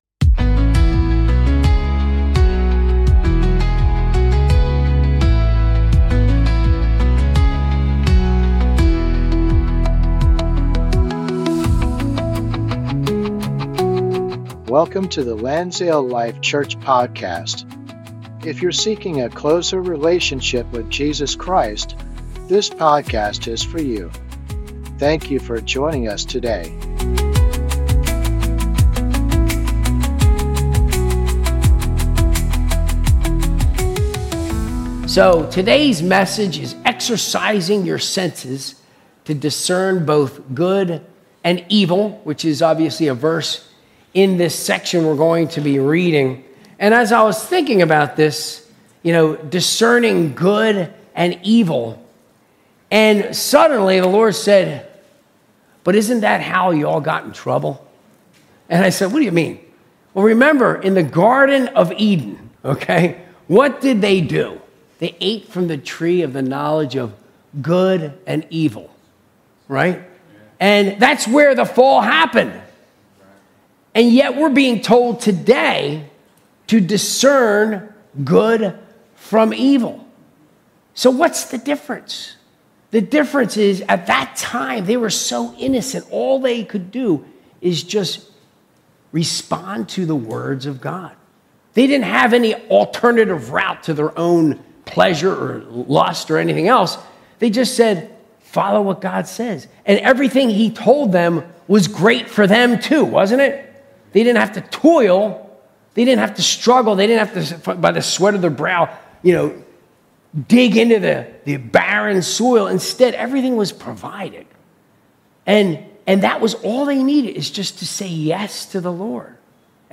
Sunday Service -2025-08-17